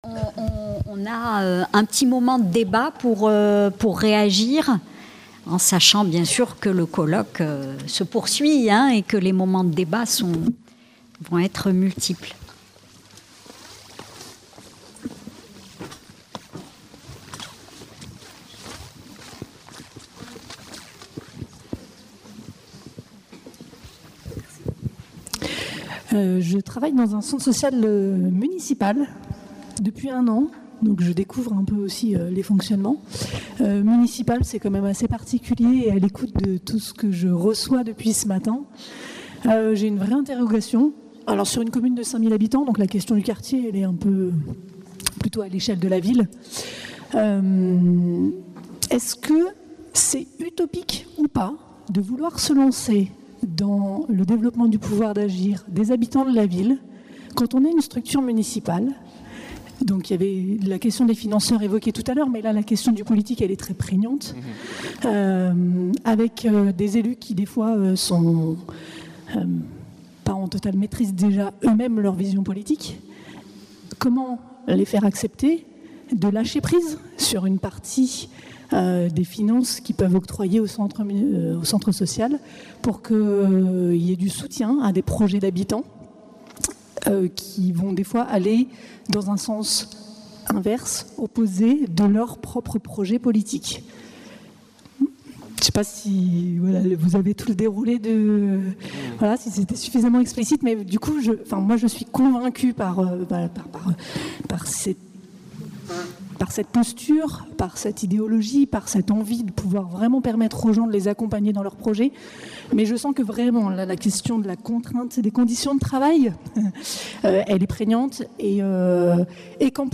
07- (Table ronde 1) : Débat avec le public (2) | Canal U